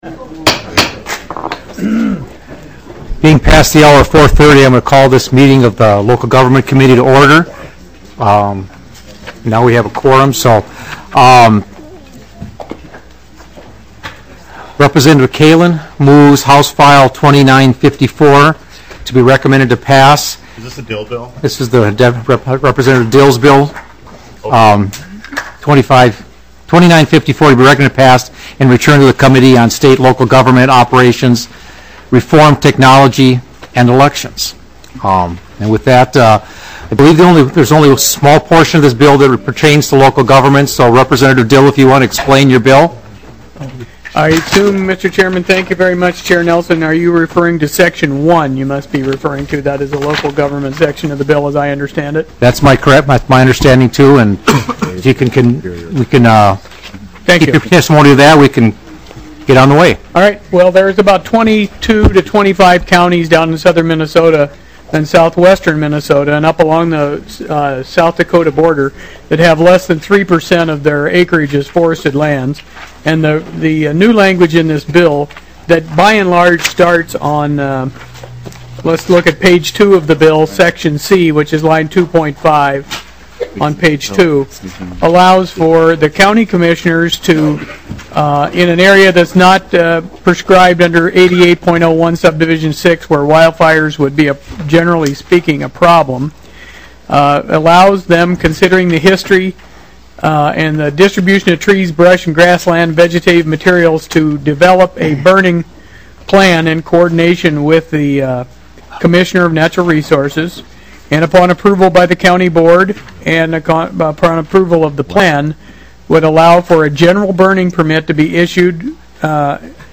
House Public Safety Policy and Oversight Committee Meeting